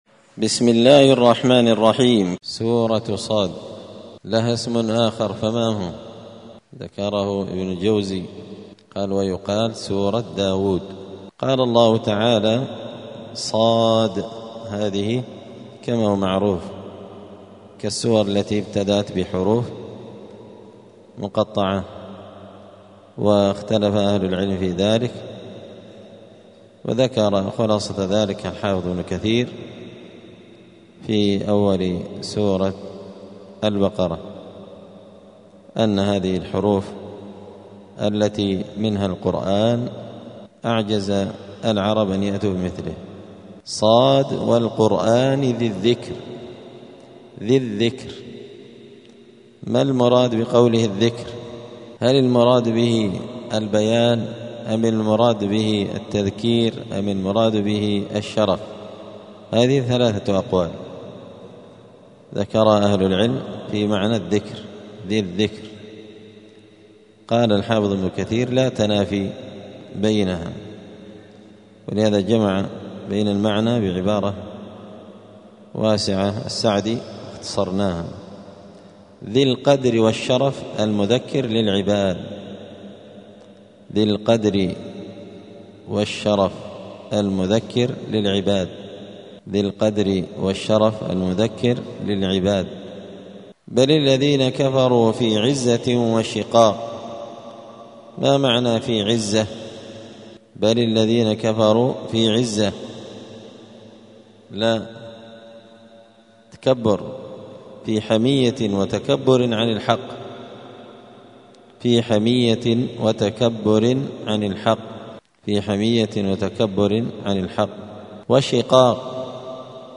زبدة الأقوال في غريب كلام المتعال الدرس الثالث والتسعون بعد المائتين (293)